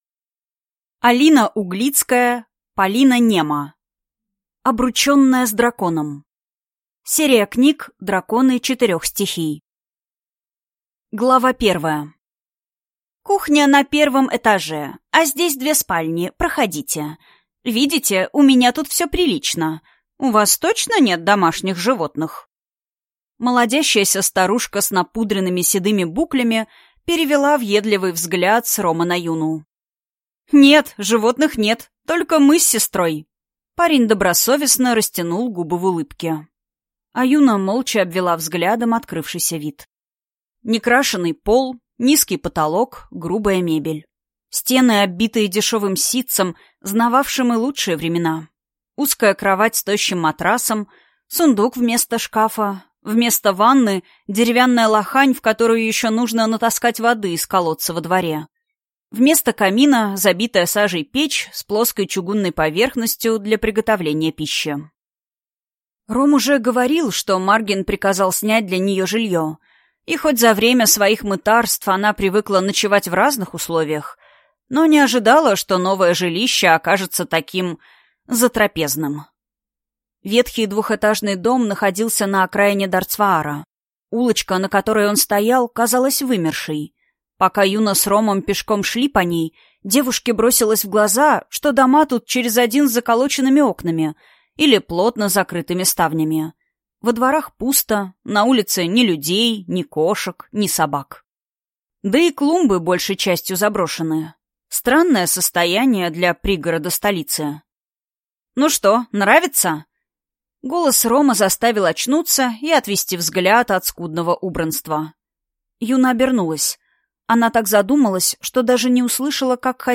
Аудиокнига Обрученная с драконом | Библиотека аудиокниг